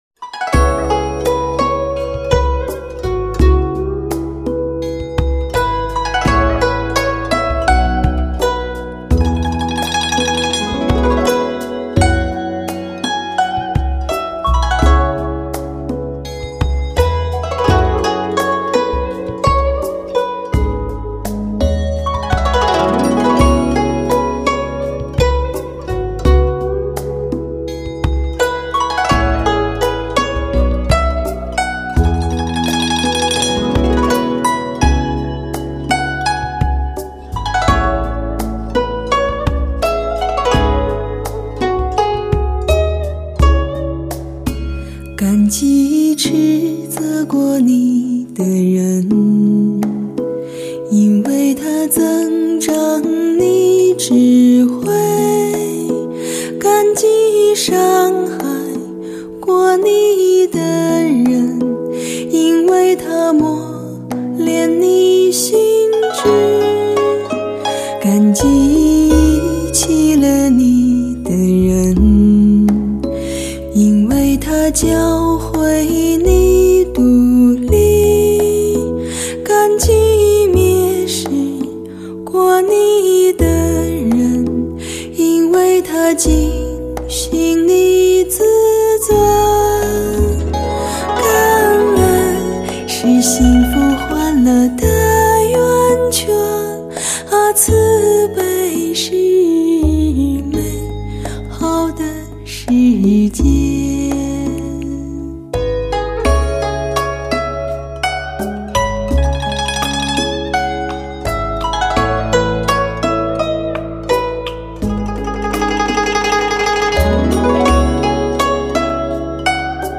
◆HD直刻无损高音质音源技术
灿烂的佛教文化，璀璨的佛教音乐。